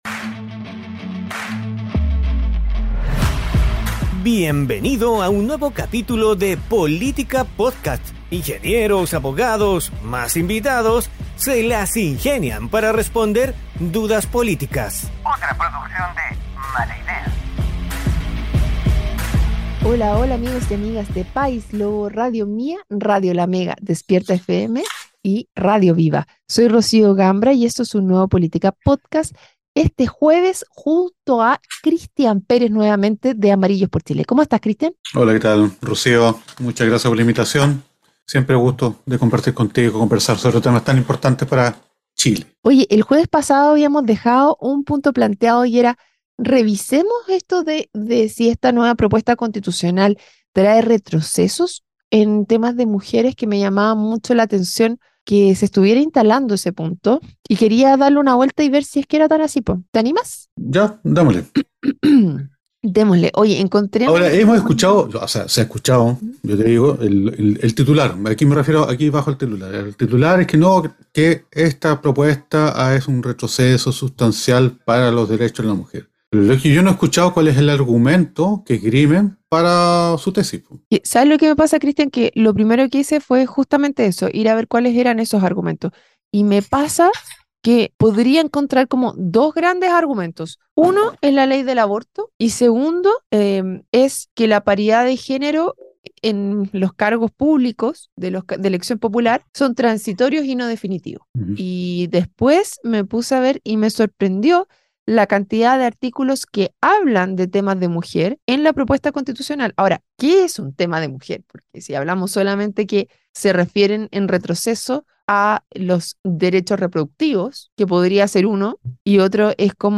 un panelista invitado